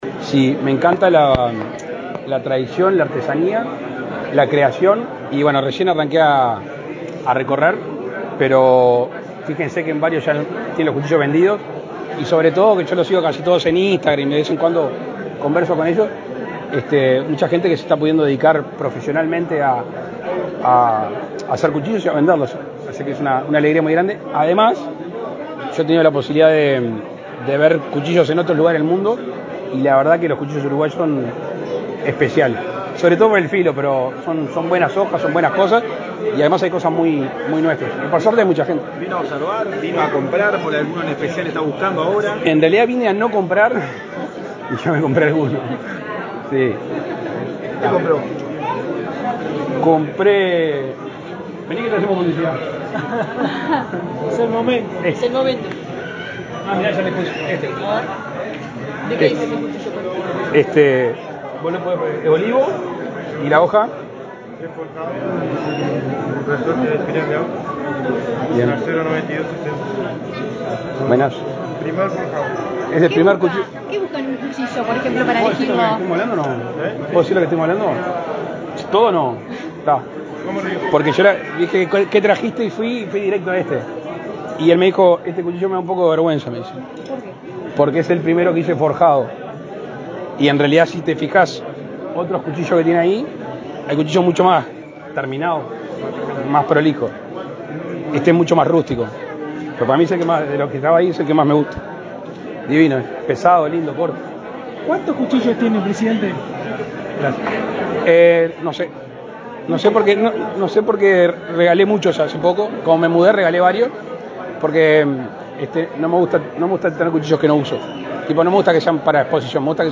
Declaraciones del presidente Lacalle Pou a la prensa
Declaraciones del presidente Lacalle Pou a la prensa 24/08/2024 Compartir Facebook X Copiar enlace WhatsApp LinkedIn El presidente de la República, Luis Lacalle Pou, dialogó con la prensa, este sábado 24, durante su visita a una exhibición de cuchillería criolla, que se realiza en el hotel Cottage de Montevideo.